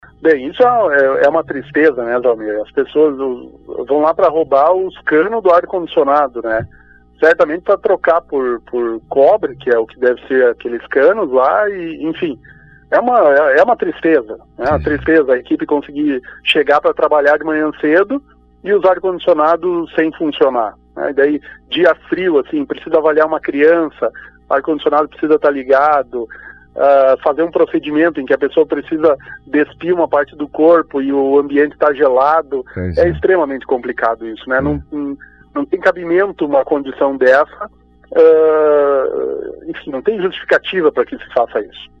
Falando hoje no Fatorama, o secretário Márcio Strasburger também lamentou o furto que ocorreu ontem no posto de saúde do bairro Herval.